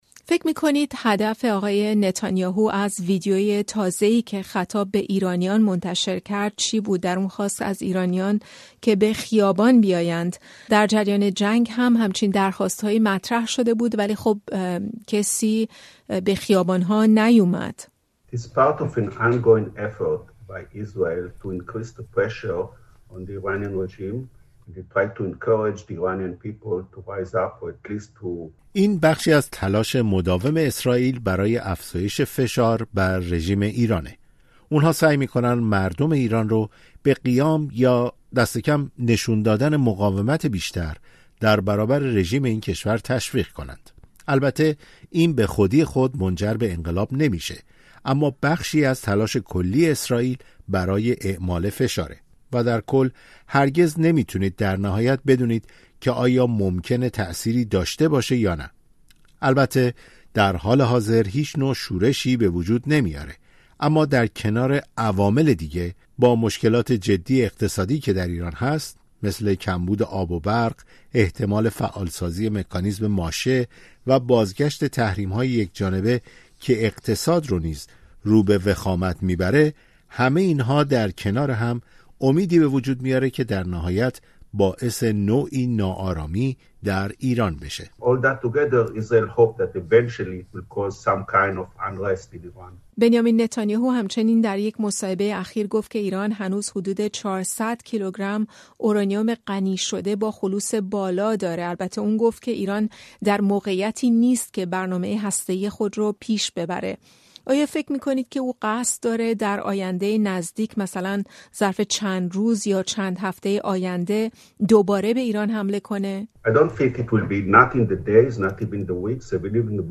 در گفت‌وگو با رادیو فردا می‌گوید اسرائیل در هفته‌ها و ماه‌های آینده به ایران حمله نخواهد کرد ولی جنگ سرد میان دو کشور ادامه خواهد یافت.